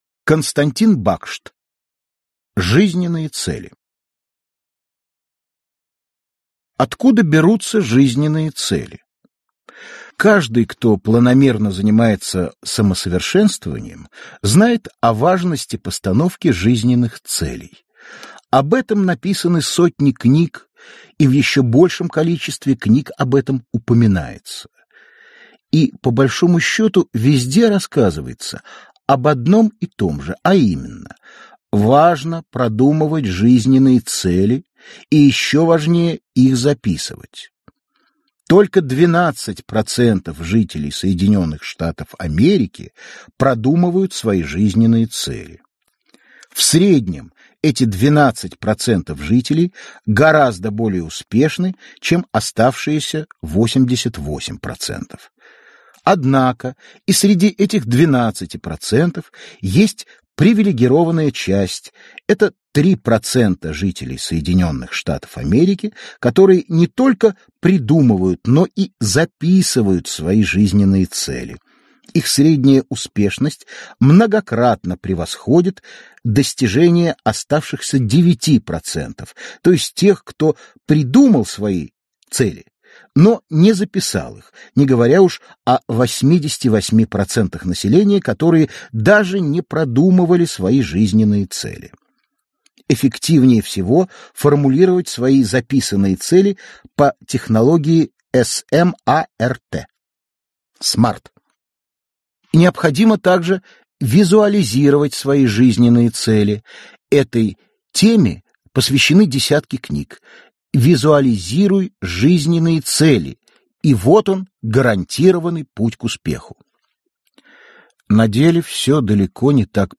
Аудиокнига Жизненные цели | Библиотека аудиокниг